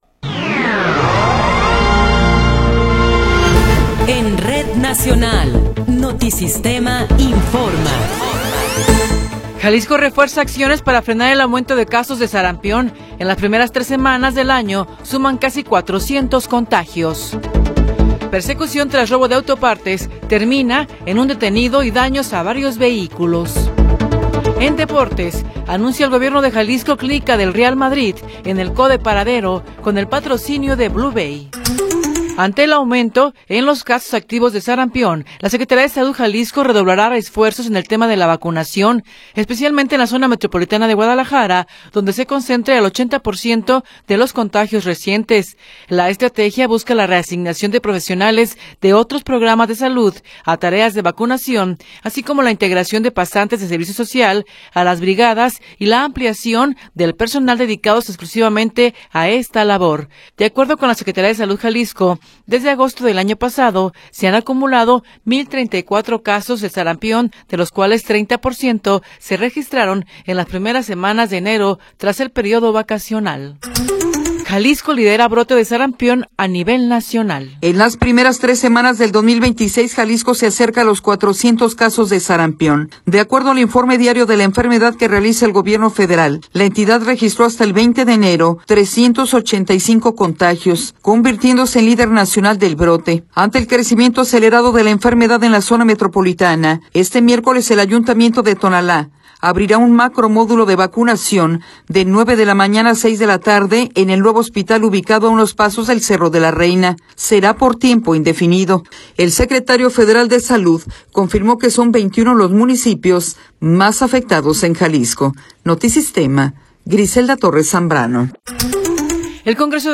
Noticiero 9 hrs. – 21 de Enero de 2026